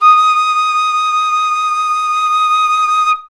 51c-flt23-D#5.wav